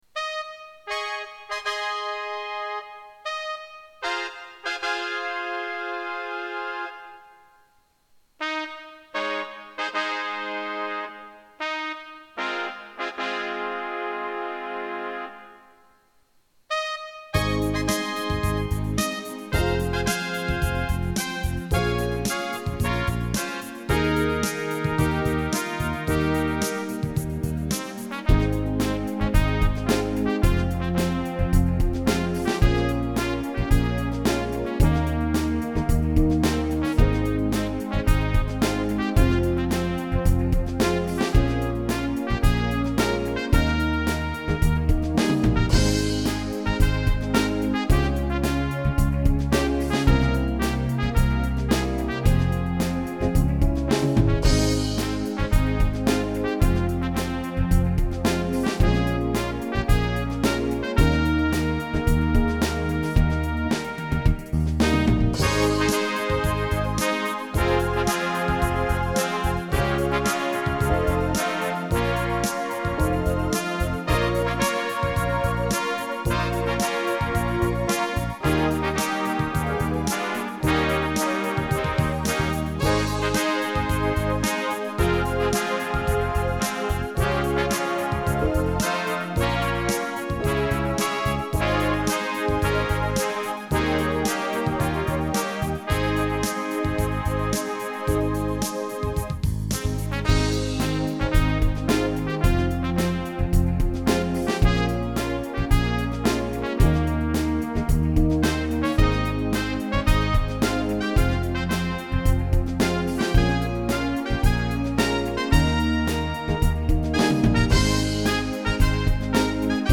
Гимн военно-спортивного отряда "ЮНАРМИИ"г. Королёва (минусовка)